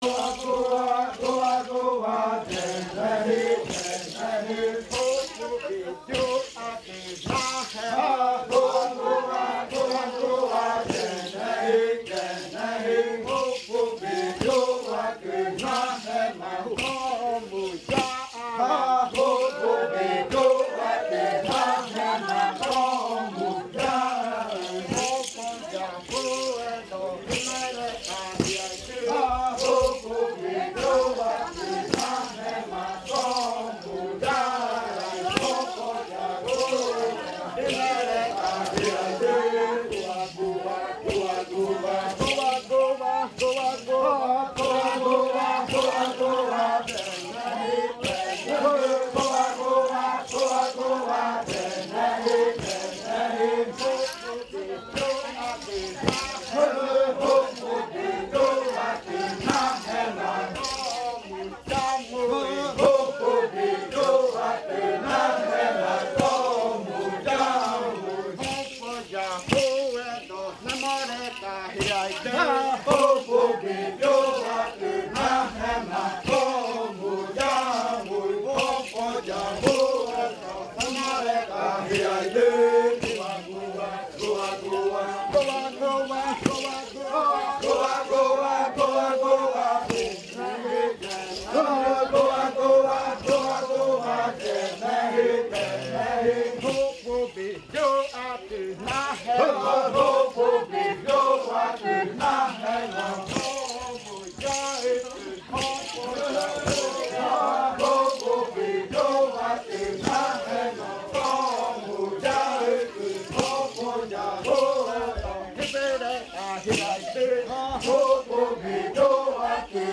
Canto de la variante muinakɨ
Leticia, Amazonas
con los cantores bailando en la Casa Hija Eetane. Este canto fue interpretado en el baile de clausura de la Cátedra de lenguas "La lengua es espíritu" de la UNAL Sede Amazonia.